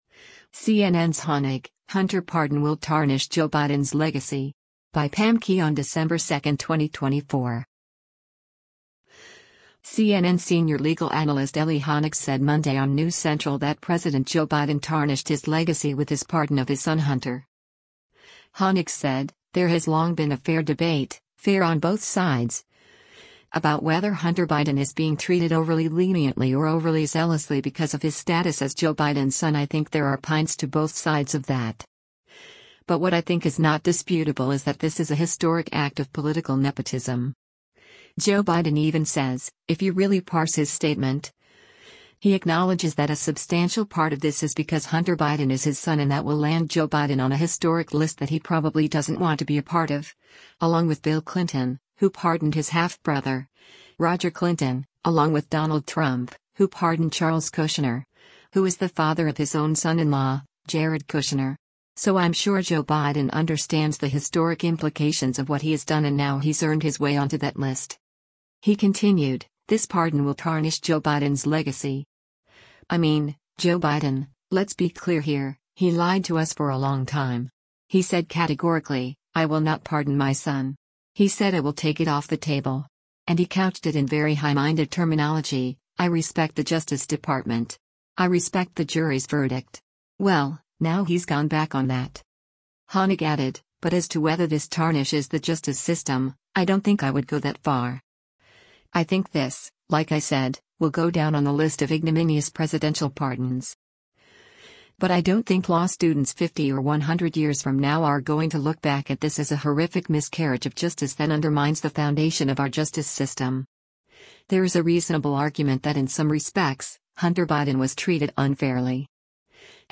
CNN senior legal analyst Elie Honig said Monday on “News Central” that President Joe Biden tarnished his legacy with his pardon of his son Hunter.